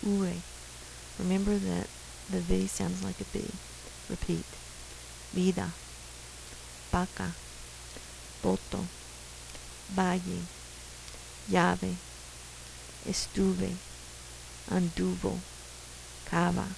Sounds that are pretty different from English
sounds like the        sounds like a kid                     sounds like a b                sounds like an s